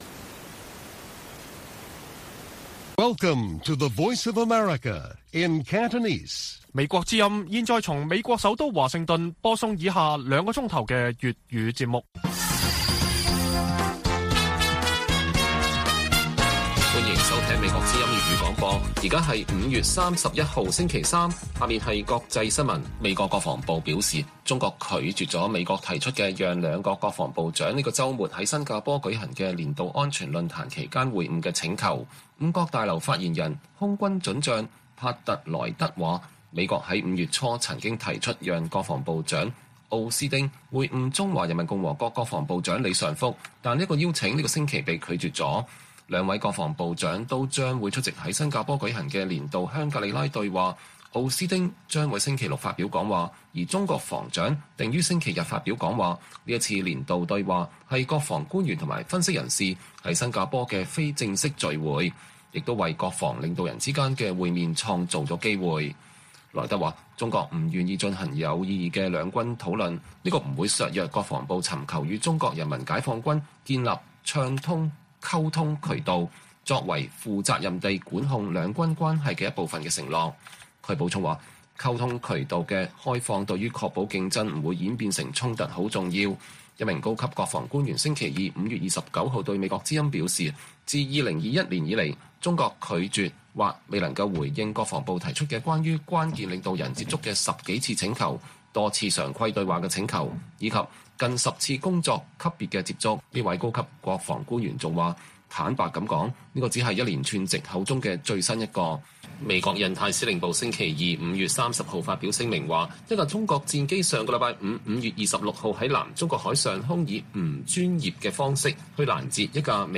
粵語新聞 晚上9-10點: 中國證實美中防長不會於本週末在新加坡會面